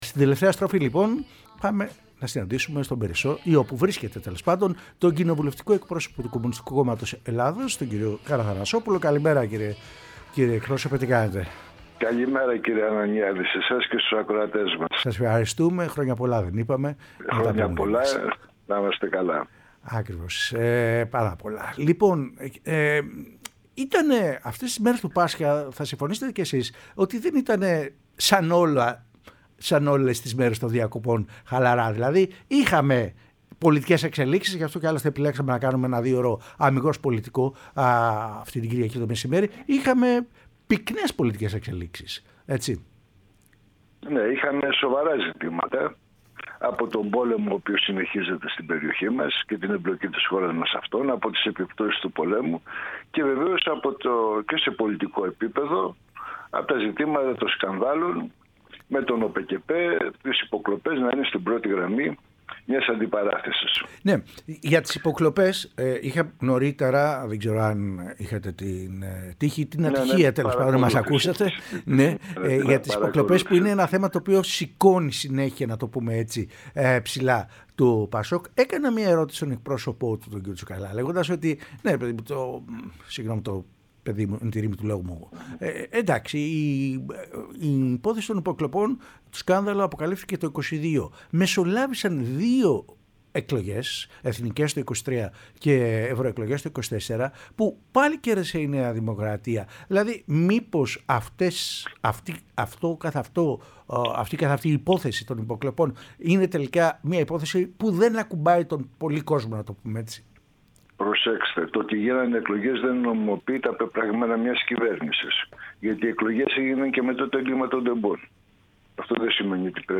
Ο Νίκος Καραθανασόπουλος, κοινοβουλευτικός εκπρόσωπος του ΚΚΕ, μίλησε στην εκπομπή “Κυριακή μεσημέρι”